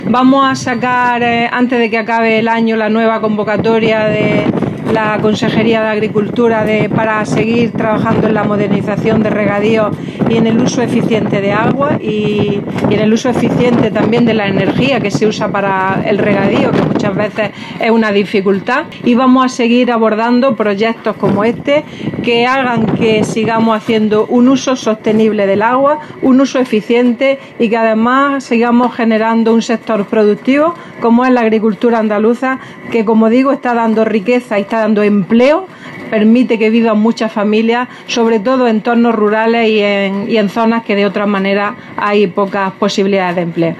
Declaraciones consejera sobre ayudas a modernización de regadíos